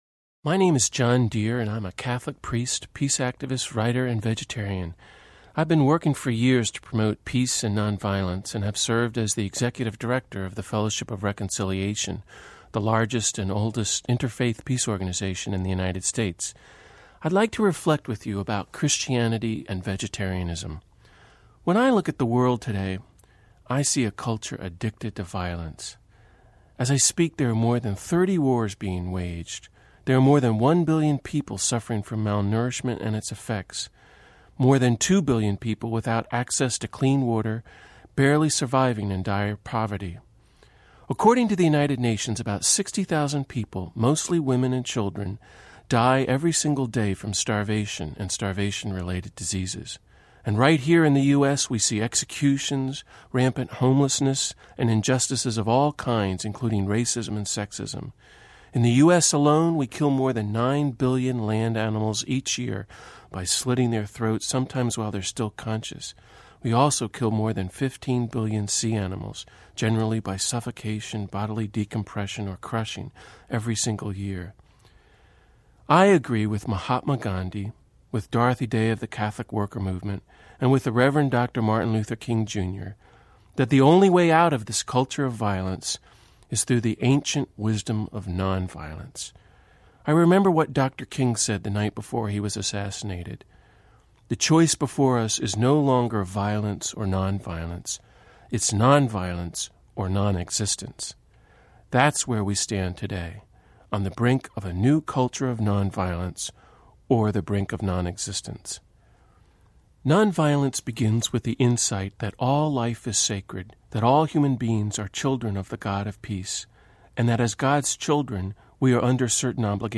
Christianity and Vegetarianism Lecture